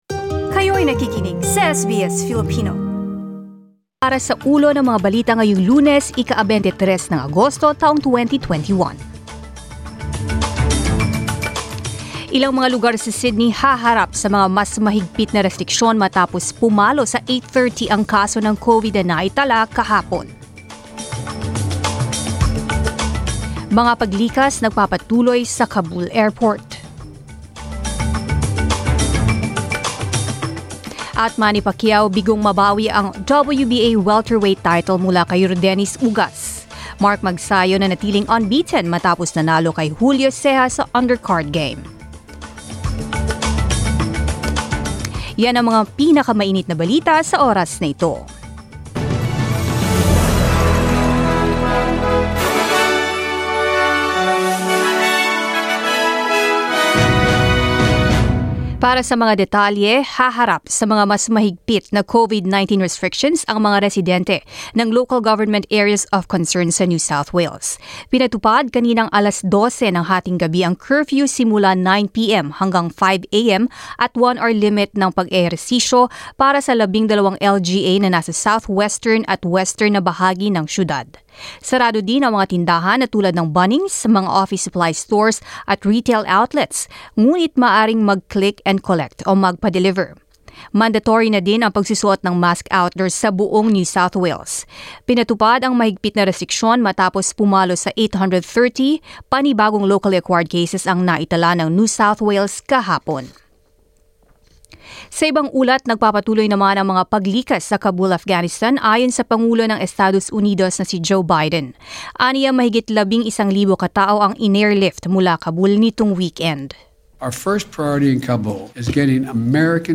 Mga Balita ngayong ika-23 ng Agosto